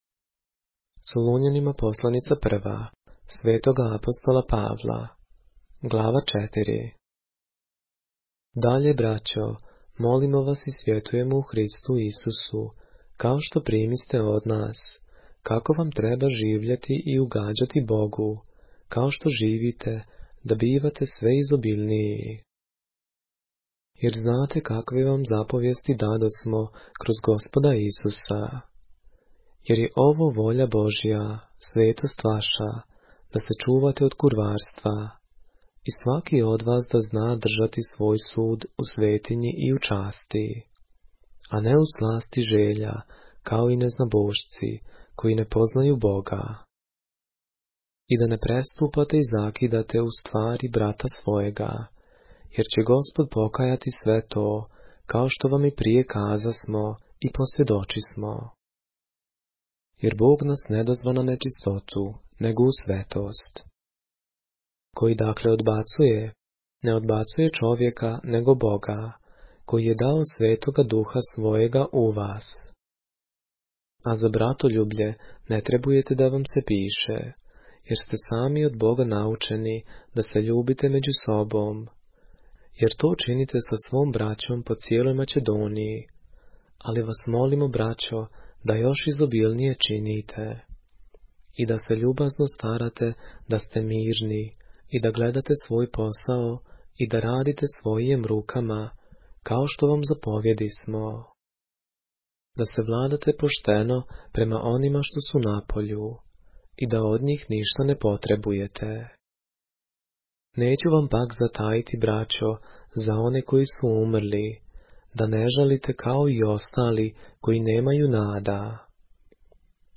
поглавље српске Библије - са аудио нарације - 1 Thessalonians, chapter 4 of the Holy Bible in the Serbian language